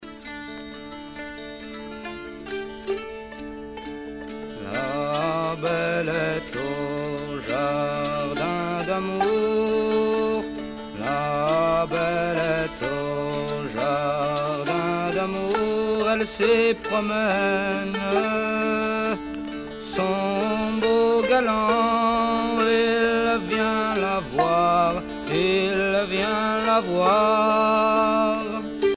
Genre strophique
Chants à répondre et à danser